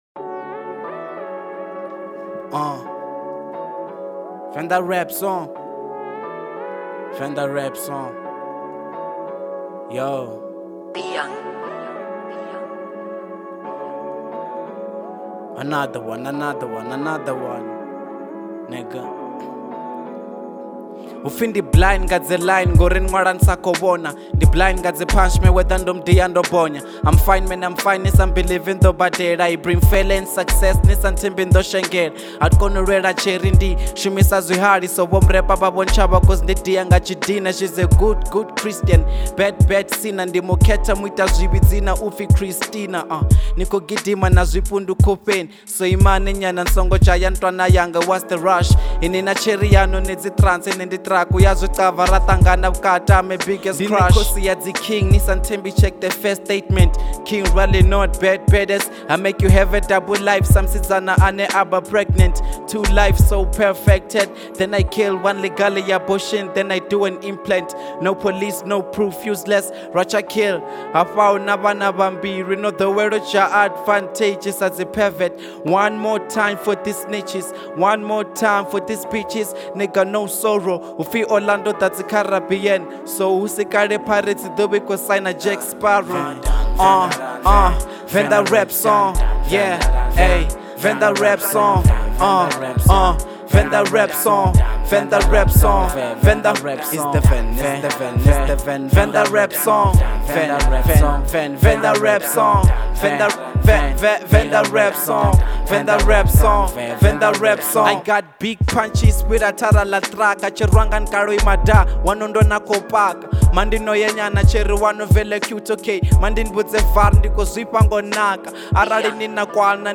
03:41 Genre : Venrap Size